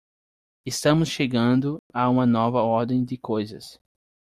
Pronounced as (IPA) /ʃeˈɡɐ̃.du/